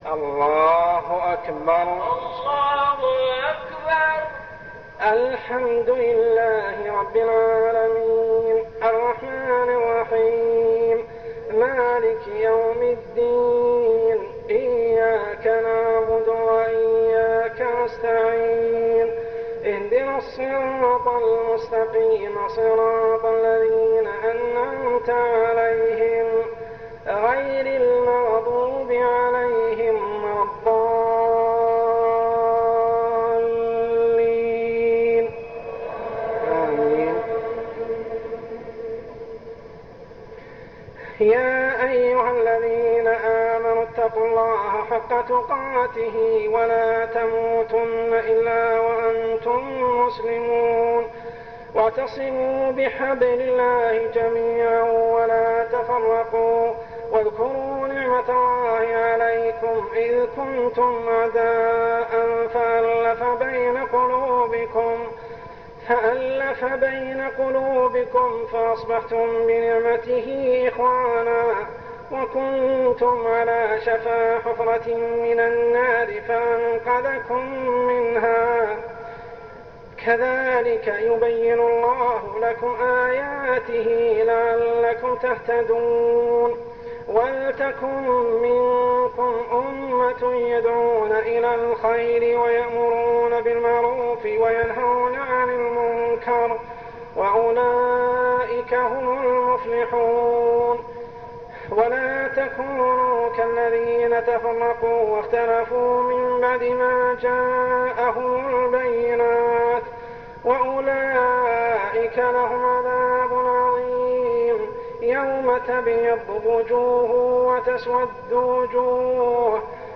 أول صلاة تراويح للشيخ ليلة 4-9-1413هـ سورة آل عمران 93-168 | Sheikh's first Tarawih prayer Surah Al Imran > تراويح الحرم المكي عام 1413 🕋 > التراويح - تلاوات الحرمين